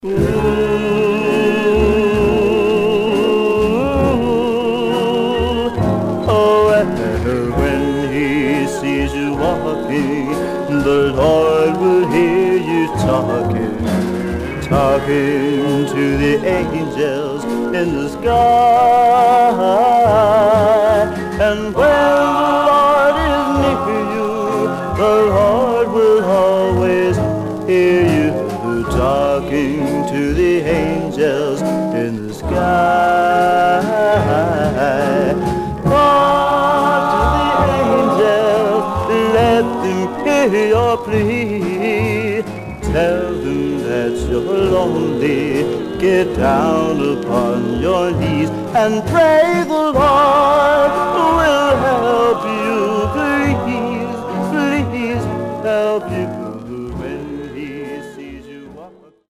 Surface noise/wear
Mono
Male Black Groups